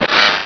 sovereignx/sound/direct_sound_samples/cries/carvanha.aif at master